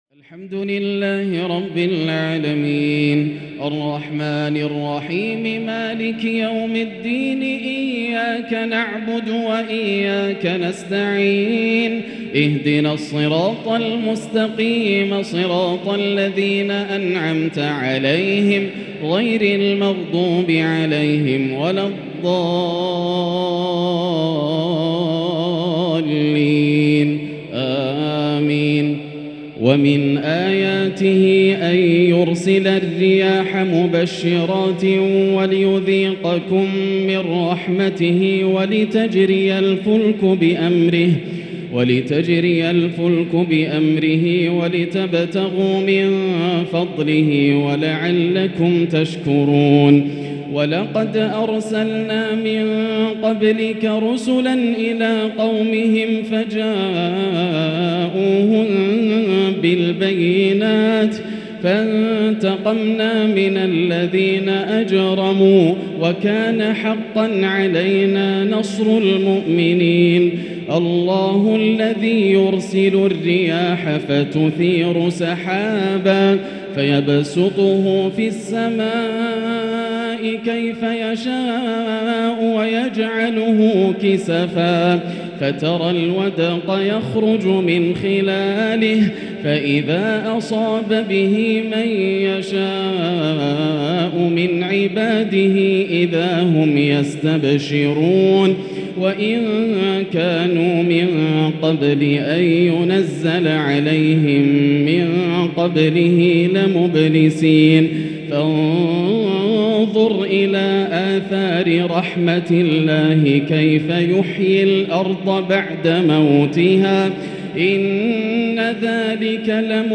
تراويح ليلة 24 رمضان 1444 آخر سورة الروم (46_60) وسورة لقمان كاملة | taraweeh prayer The 24th night of Ramadan 1444H | from surah Ar-Rum and Luqman > تراويح الحرم المكي عام 1444 🕋 > التراويح - تلاوات الحرمين